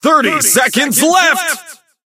30secondsleft_vo_01.ogg